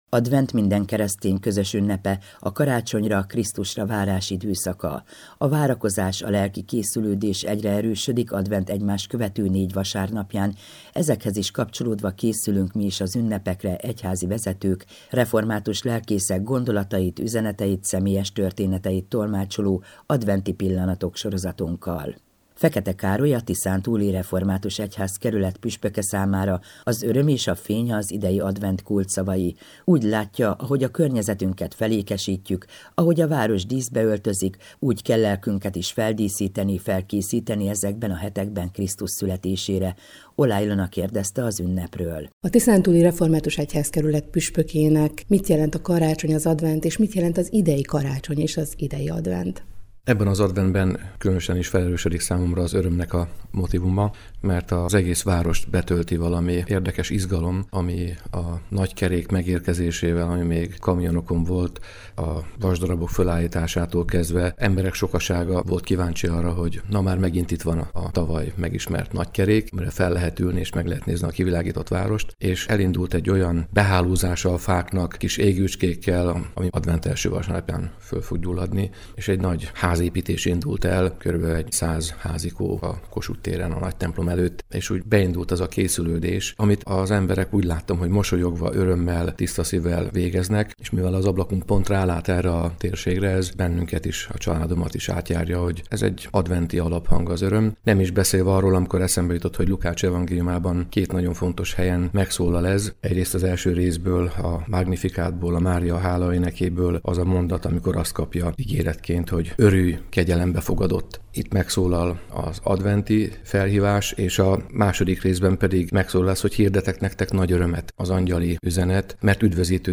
Adventi gondolatok Fekete Károlytól, a Tiszántúli Református Egyházkerület püspökétől az Európa Rádióban .